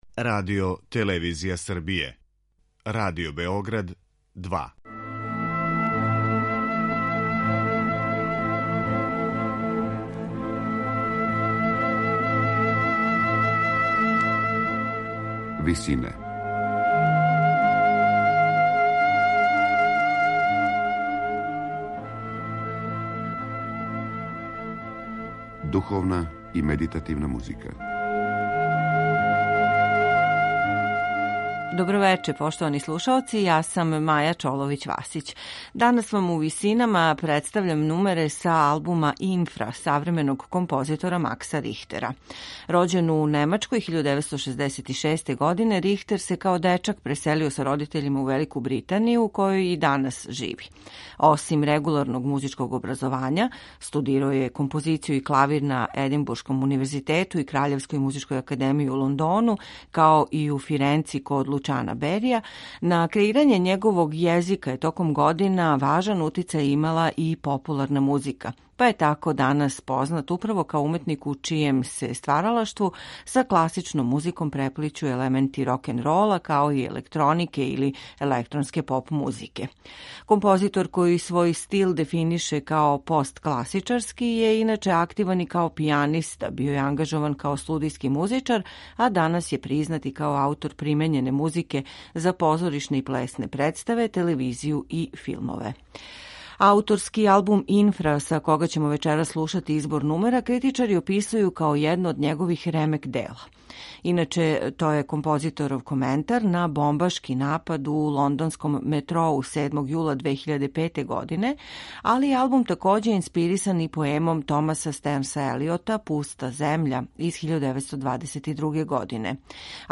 који свој стил дефинише као посткласични.
за клавир, електронику и гудачки квартет